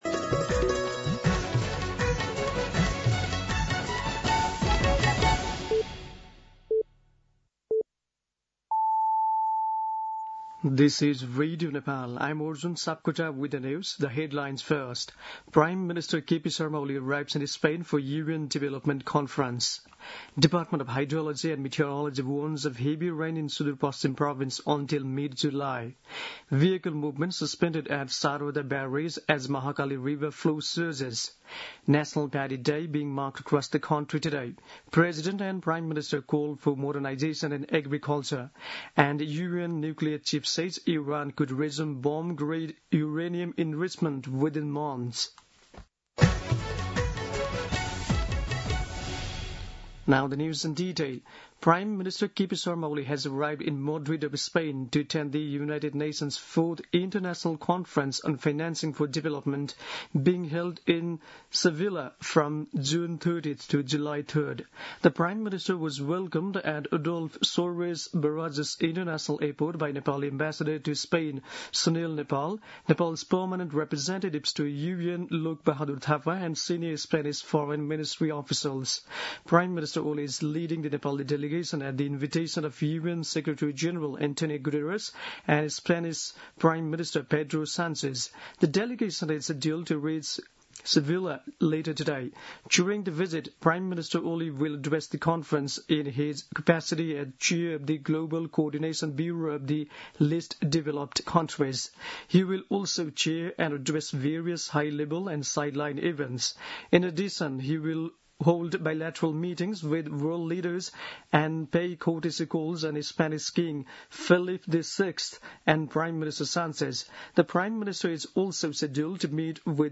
दिउँसो २ बजेको अङ्ग्रेजी समाचार : १५ असार , २०८२
2-pm-English-News-3-15.mp3